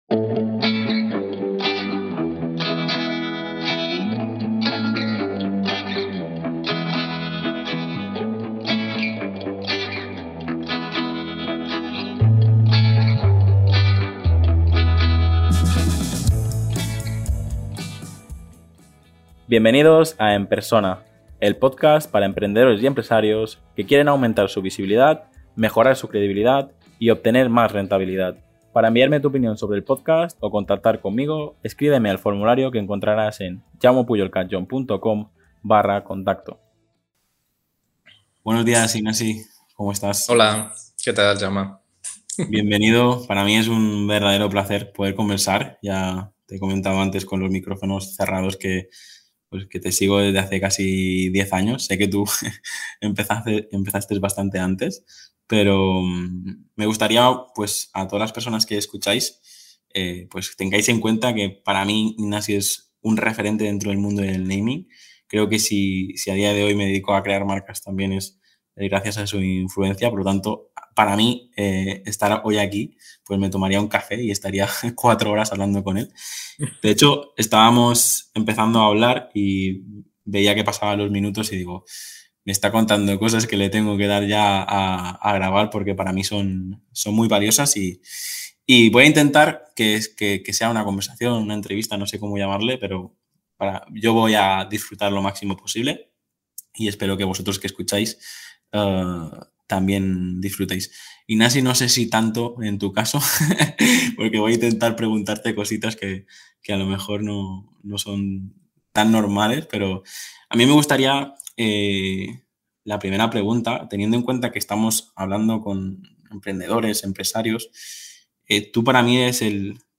Durante la entrevista, en ningún momento tuvo intención de cortarme y se alargó todo el tiempo que quise.